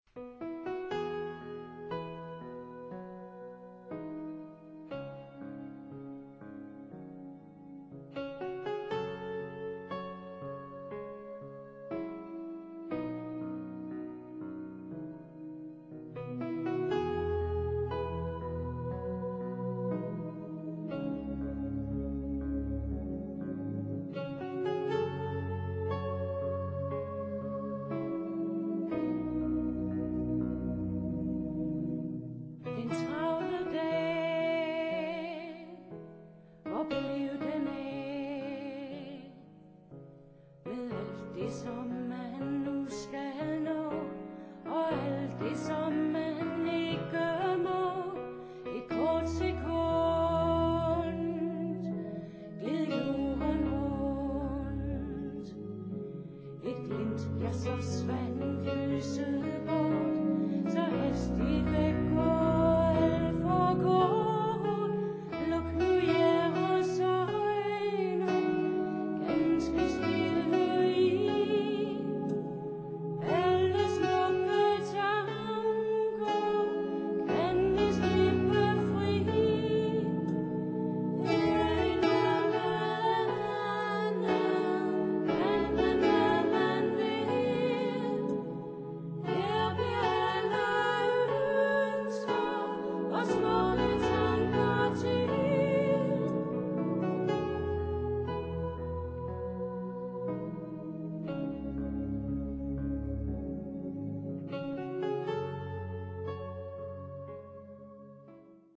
Vuggesang